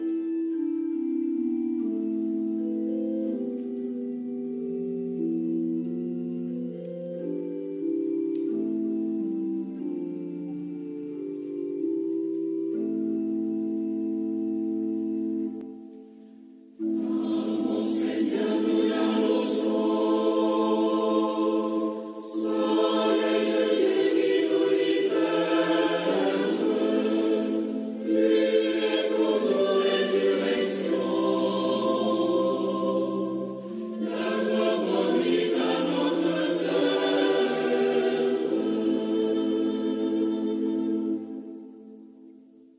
Anamnèse
P : C. Bernard / M : B. Bayle